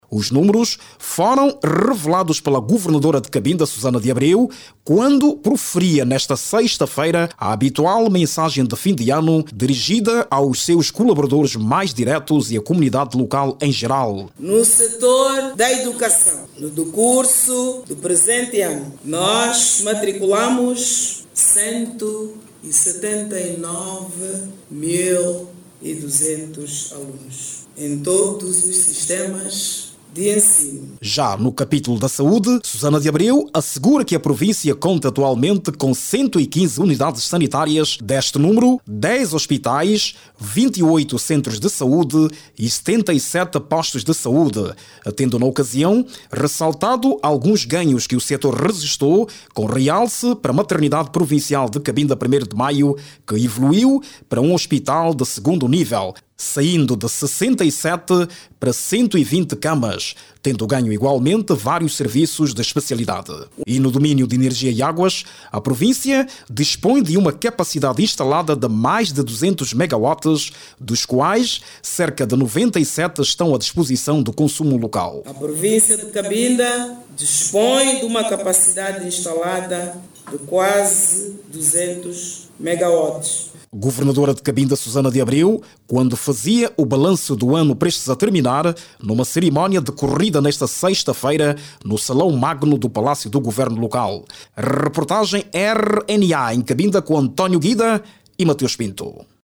A Governadora de Cabinda faz balanço positivo do ano de 2025, sobre os ganhos que a província obteve nos sectores da educação, saúde e energia eléctrica. Suzana Abreu, fez este anúncio, durante a cerimónia de apresentação do balanço que decorreu no salão nobre do governo local.